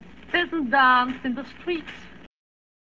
I Saw My Country Die: A Radio Interview with Jarmila Novotna